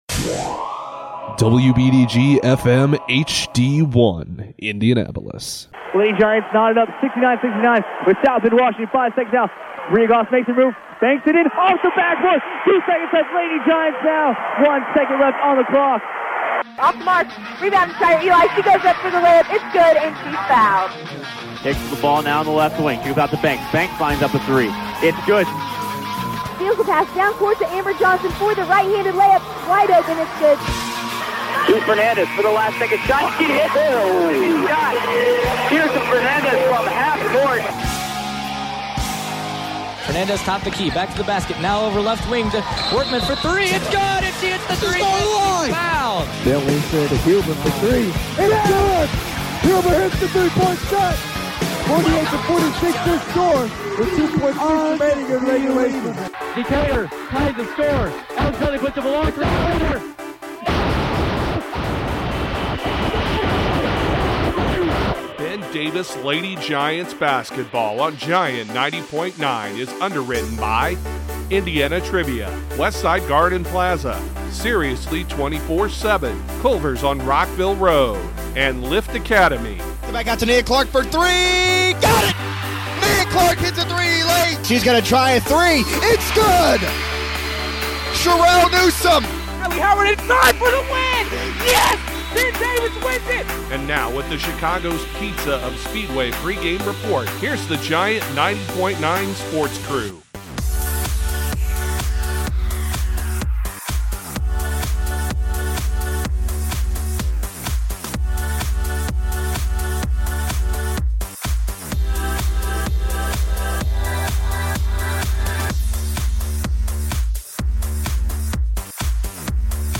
Our 2020/2021 open for Ben Davis Lady Giants Basketball includes our traditional open done several years ago but updated with new highlights and calls from recent games.